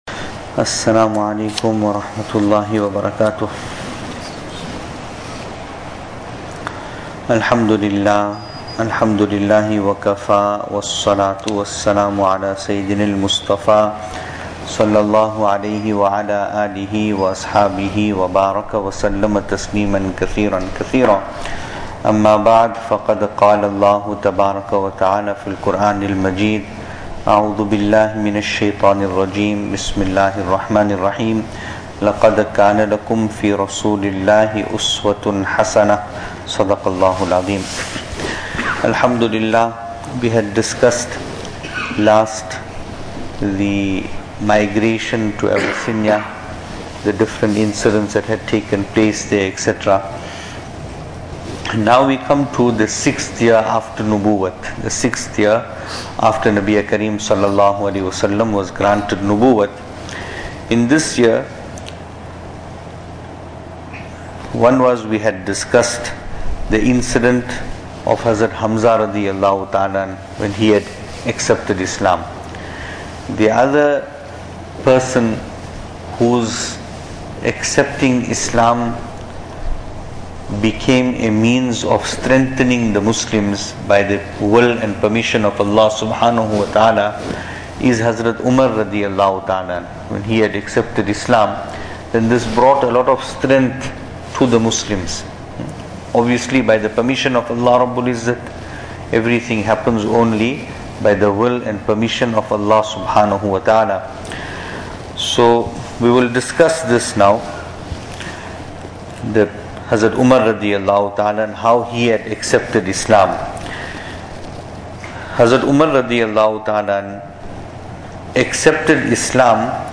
Venue: Masjid Taqwa, Pietermaritzburg | Series: Seerah Of Nabi (S.A.W)